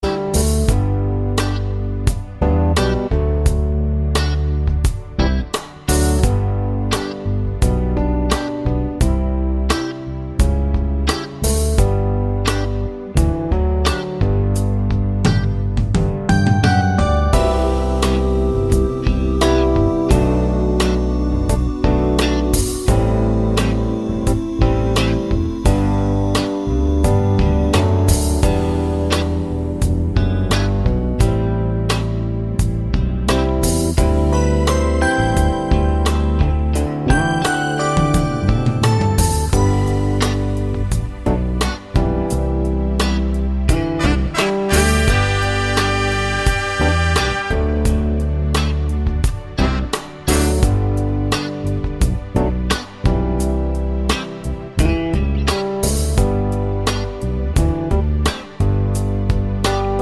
Minus Sax Solo Pop (1970s) 4:25 Buy £1.50